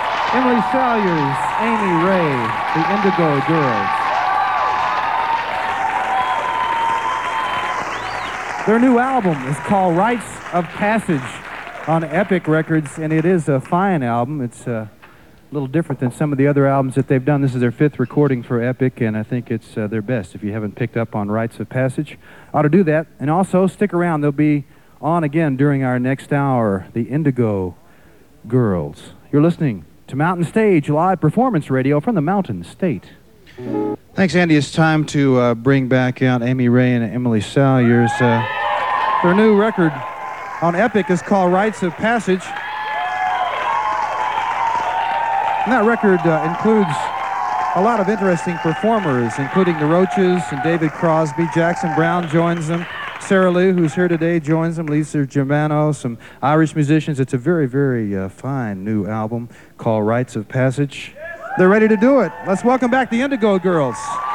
06. announcer (1:04)